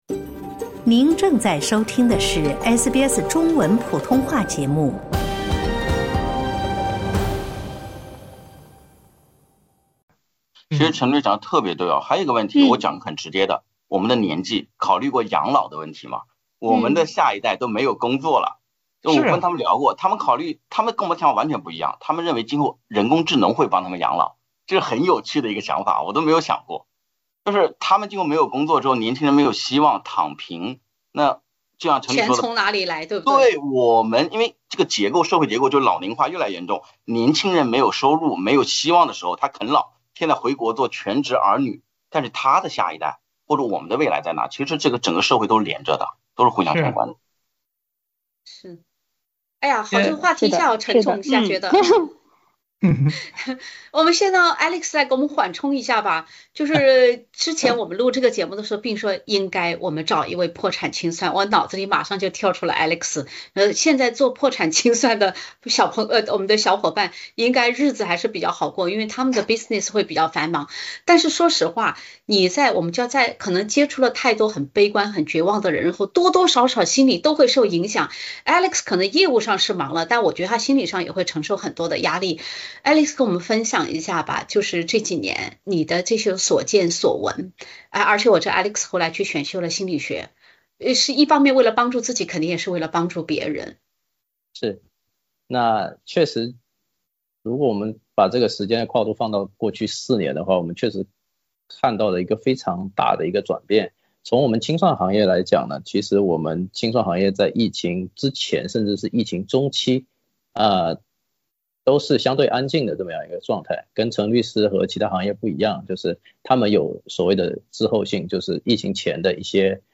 当AI抢跑你的简历、甚至代写合同时，我们的工作还能稳稳在线吗？后浪嘉宾结合自己工作分享担忧与期待。